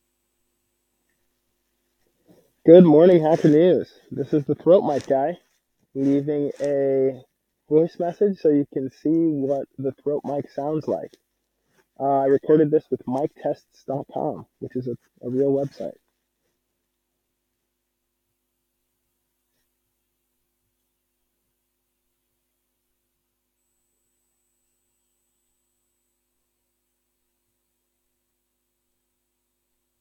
hn-throat-mic-test.ogg